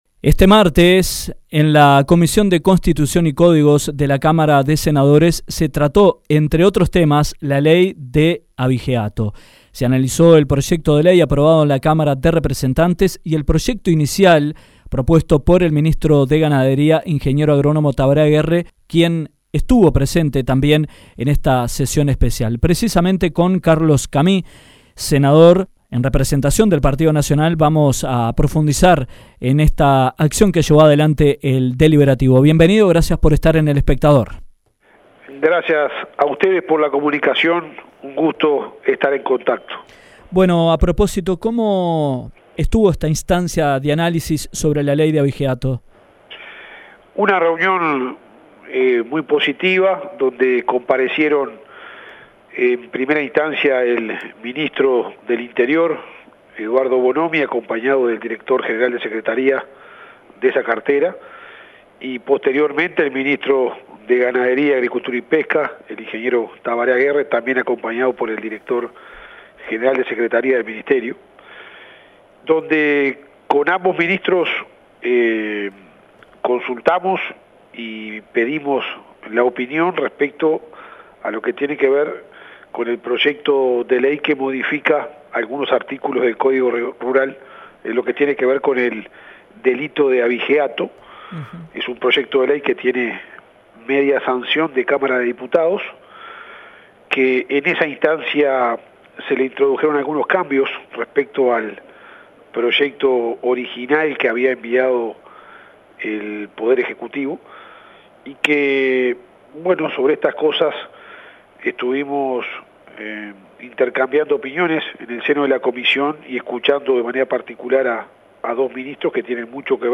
Este martes en la Comisión de Constitución y Códigos de la Cámara de Senadores de la República, con la presencia de los ministros de Ganadería, e interior Ing. Agr. Tabaré Aguerre y Eduardo Bonomi respectivamente, se analizó la ley sobre abigeato. En diálogo con Dinámica Rural, el senador Carlos Camy dijo que se trató el proyecto de ley que cuenta con media sanción de la Cámara de Diputados y el proyecto inicial propuesto por el ministro Aguerre.